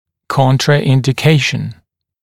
[ˌkɔntrəˌɪndɪ’keɪʃn][ˌконтрэˌинди’кейшн]противопоказание